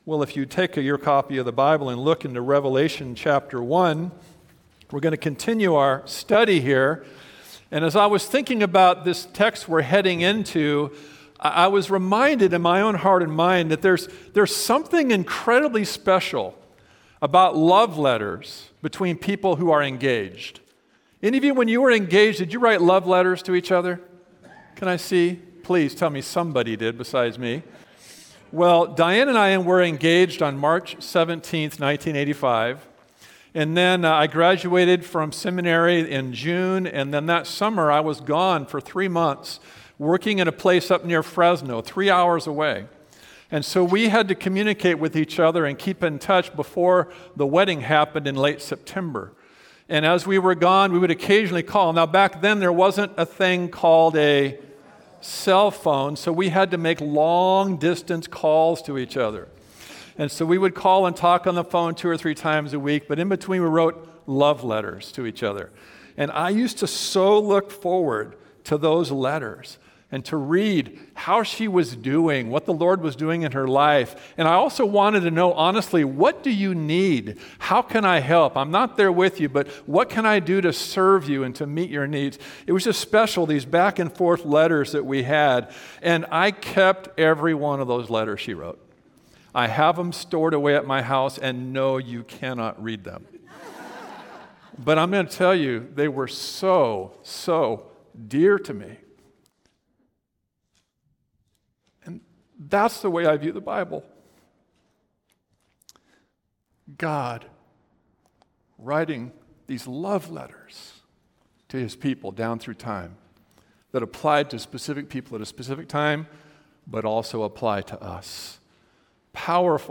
Service Type: Sunday Worship Service
Sermon